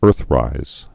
(ûrthrīz)